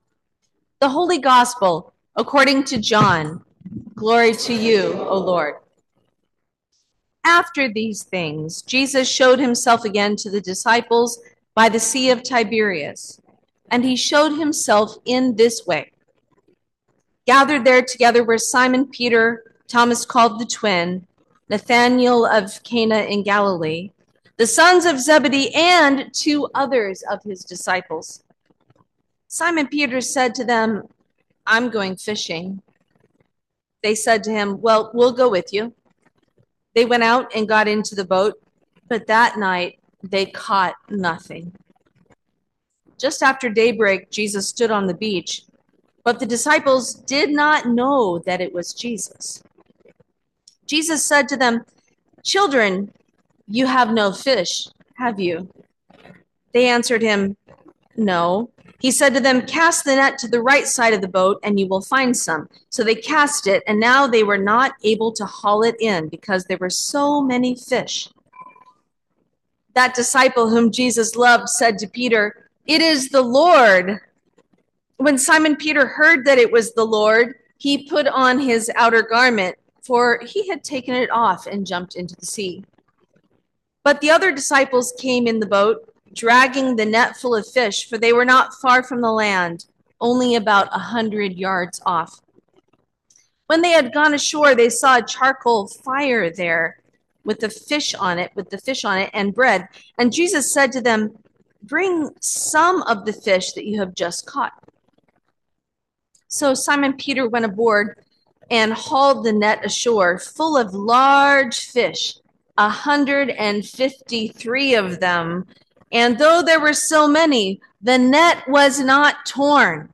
Sermon for the Third Sunday of Easter 2025